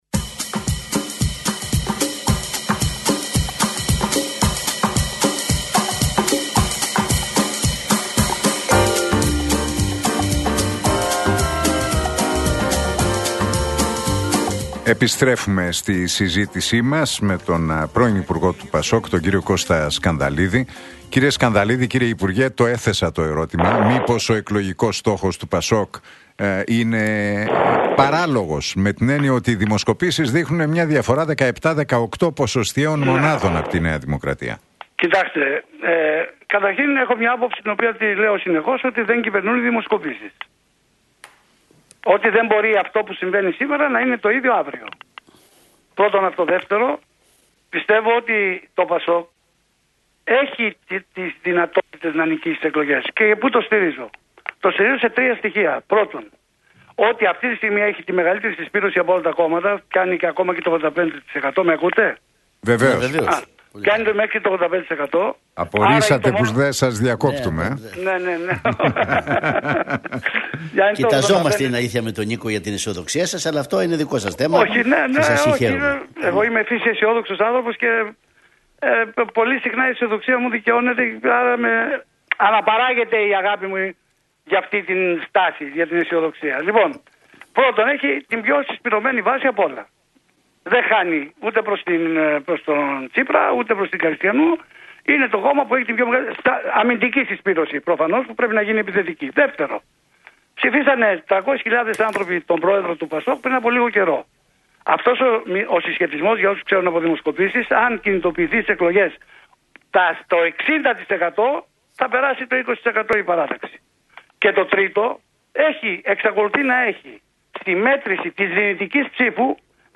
Ακούστε την εκπομπή του Νίκου Χατζηνικολάου στον ραδιοφωνικό σταθμό RealFm 97,8, την Πέμπτη 12 Μαρτίου 2026.